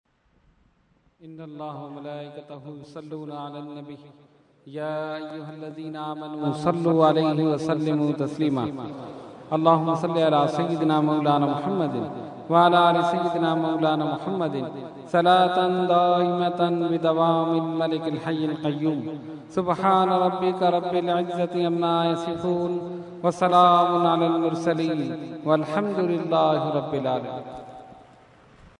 Dua – Urs Makhdoome Samnani 2012 Day 2 – Dargah Alia Ashrafia Karachi Pakistan